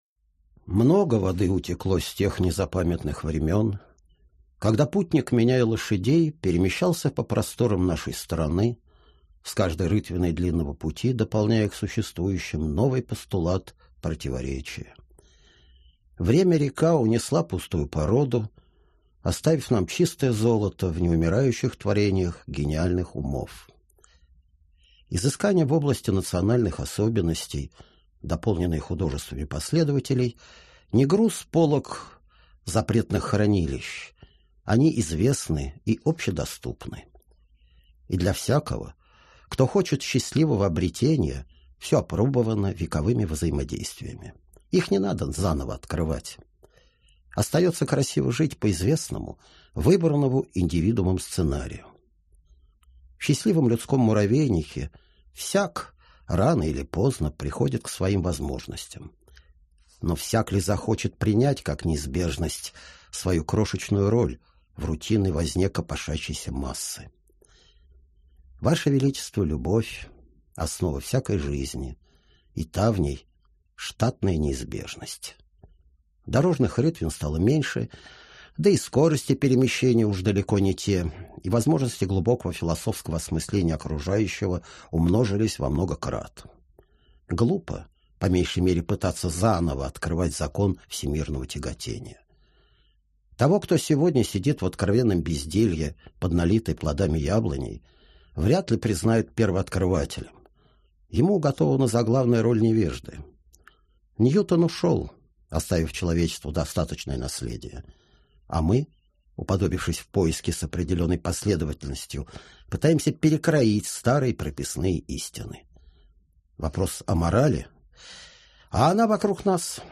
Аудиокнига Гражданин Земли | Библиотека аудиокниг
Прослушать и бесплатно скачать фрагмент аудиокниги